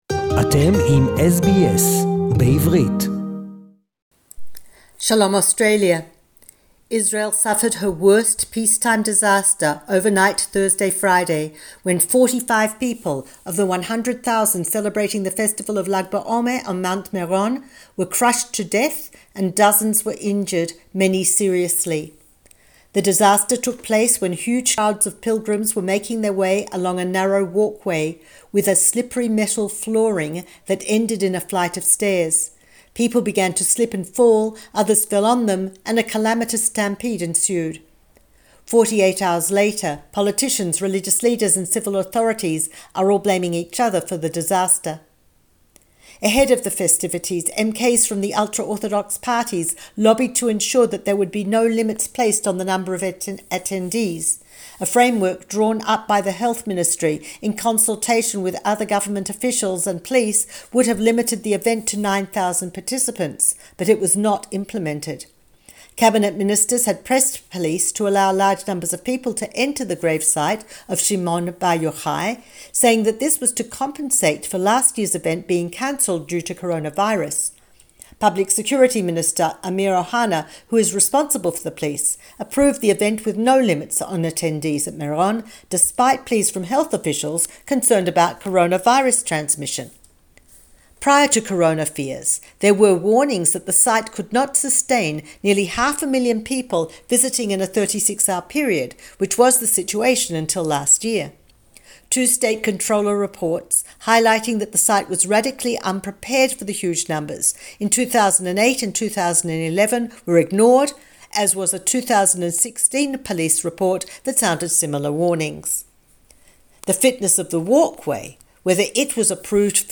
SBS Jerusalem report